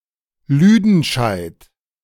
Lüdenscheid (German pronunciation: [ˈly:dn̩ʃaɪt]